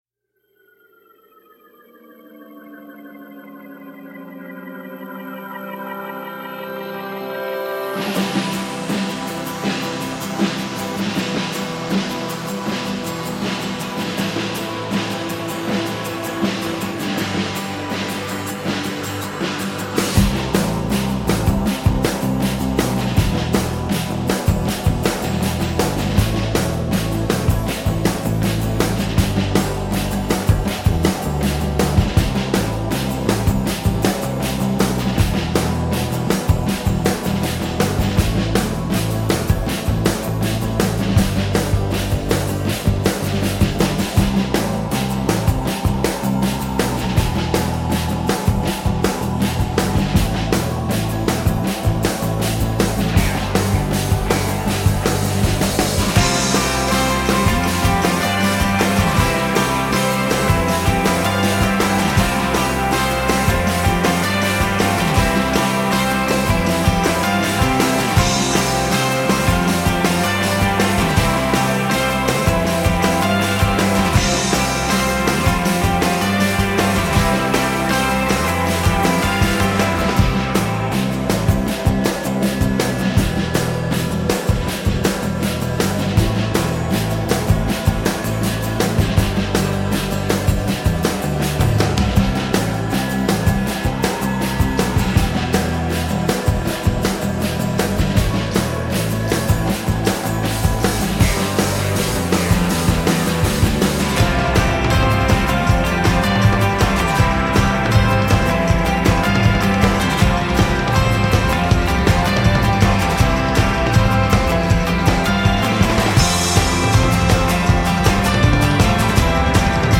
Hook-laden anthems of survival and triumph.
Tagged as: Alt Rock, Hard Rock, Grungy Rock, Instrumental